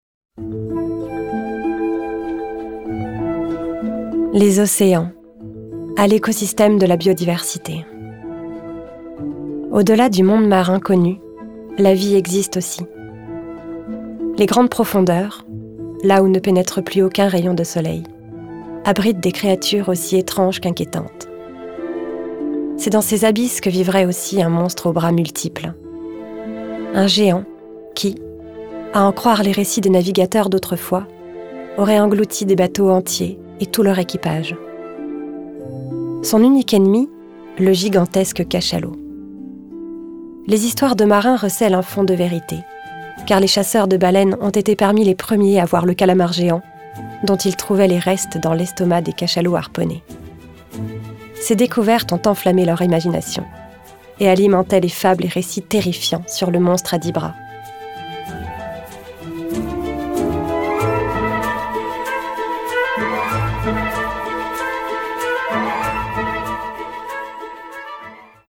Voix off
Comédienne ciné, doublage et voix off